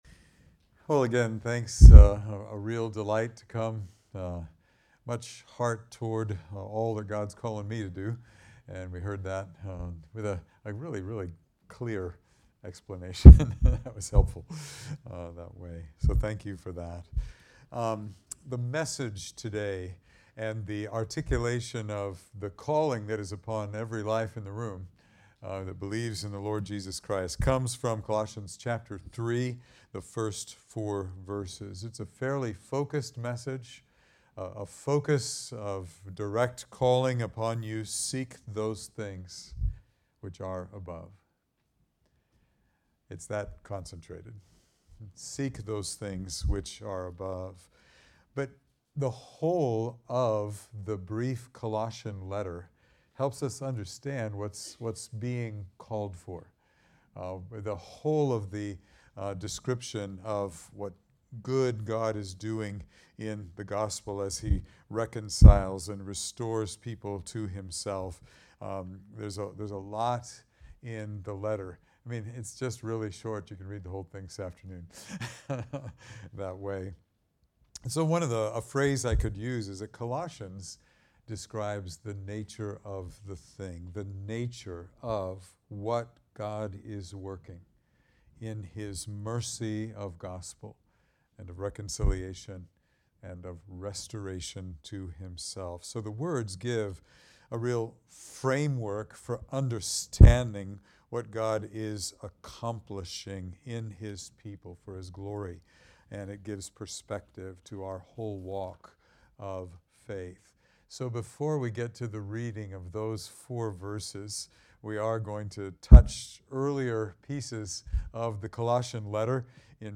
Passage: Colossians 3:1-4 Service Type: Sunday Morning Download Files Bulletin « Knowing a Holy God The Calling of the Twelve